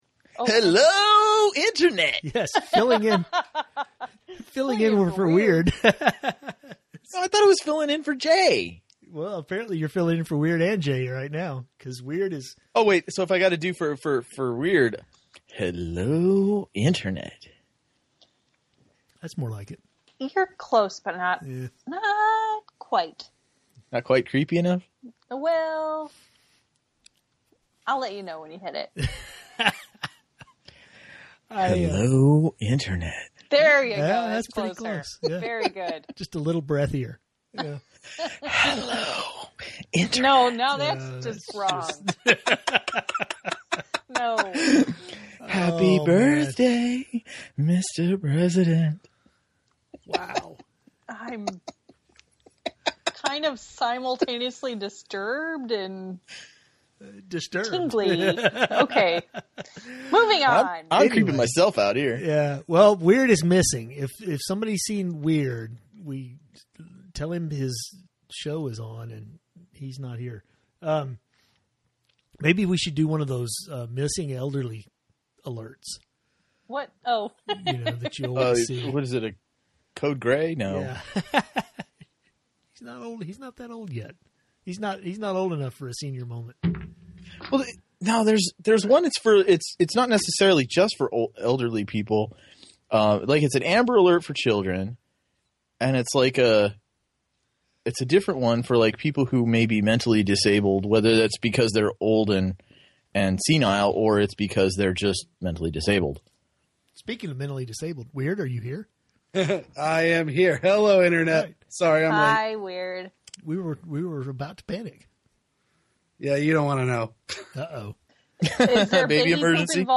Tonight’s call in topic was: Caption this photo!